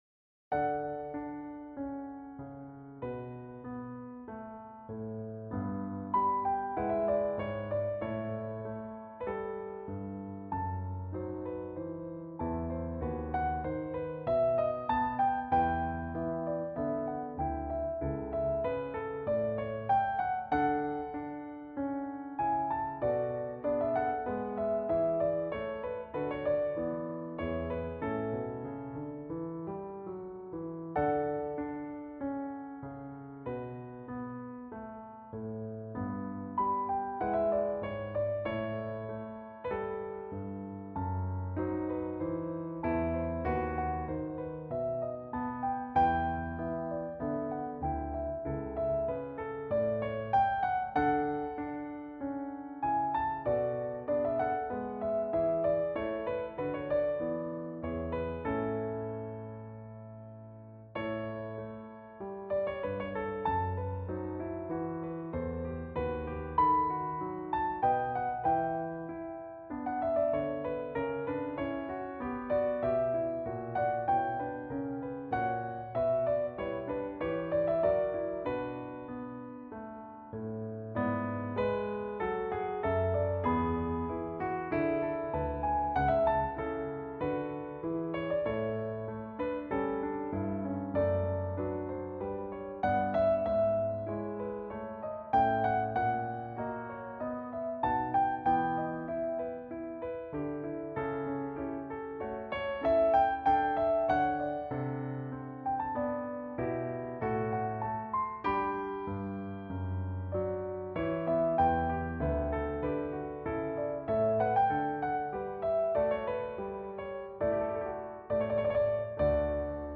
A few slurs, as in the original score but that’s it:
Pretty jaw-dropping for a computer playback.